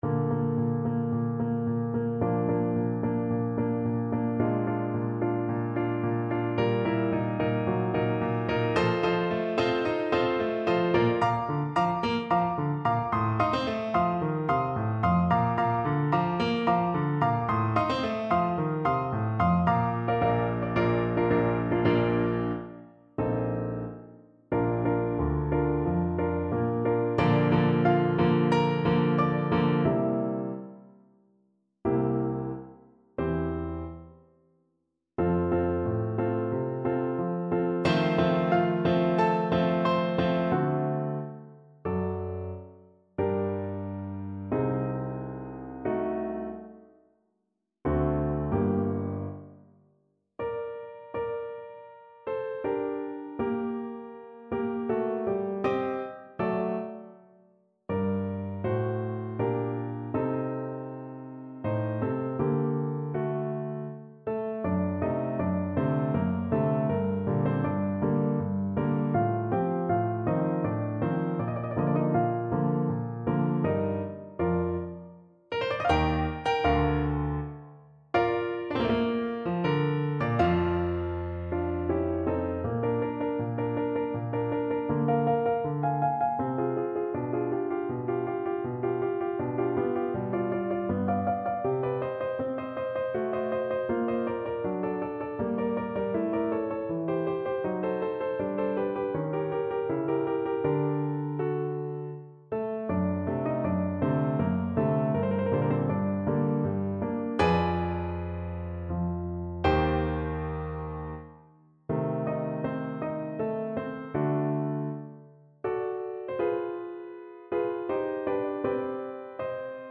Allegro maestoso =110 (View more music marked Allegro)
Classical (View more Classical Soprano Voice Music)